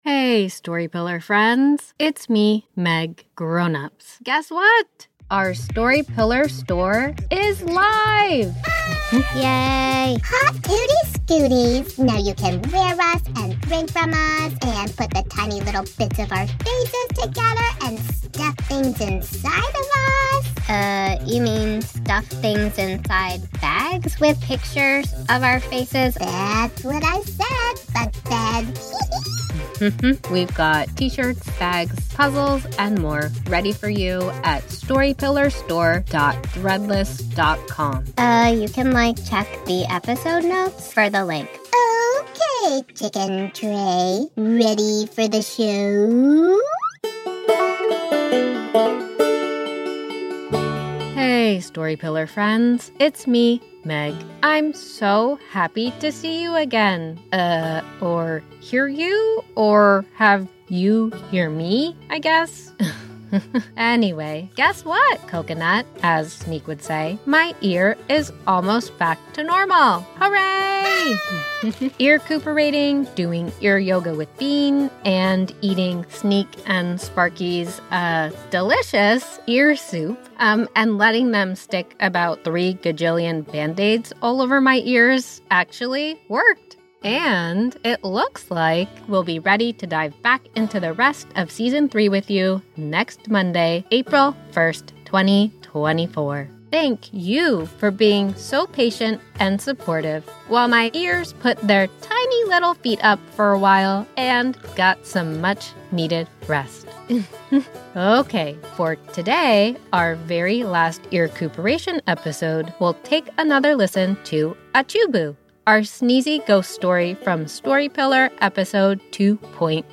-Calming Piano Loop:
-Cheerful Instrumental Song:
-Slightly Spooky Song: